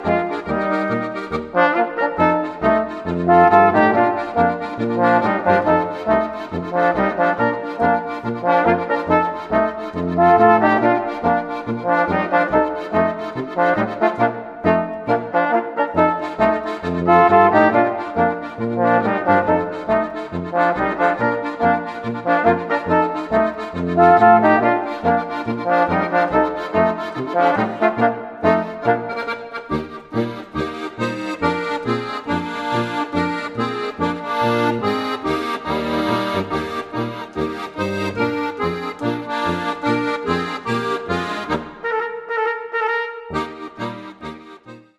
Besetzung: Volksmusik/Volkstümlich Weisenbläser
Akkordeon
Trompete
Tuba
Basstrompete